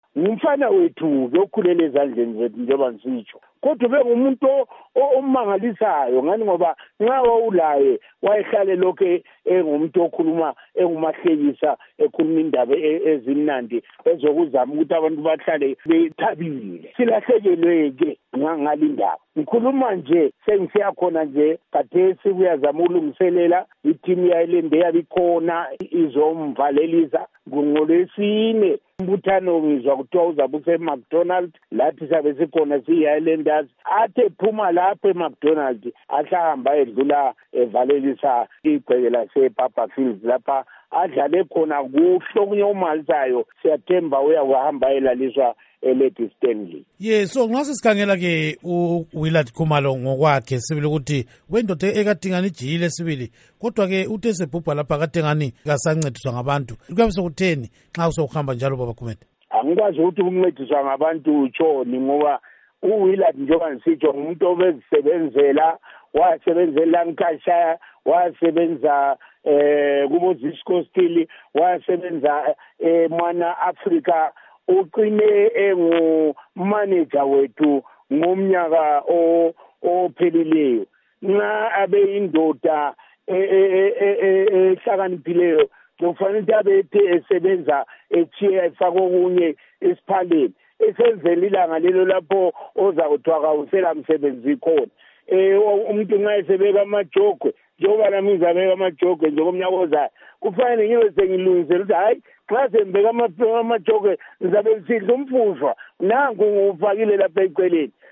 Ingxoxo Esiyenze LoMnu